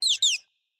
Minecraft Version Minecraft Version snapshot Latest Release | Latest Snapshot snapshot / assets / minecraft / sounds / mob / dolphin / play1.ogg Compare With Compare With Latest Release | Latest Snapshot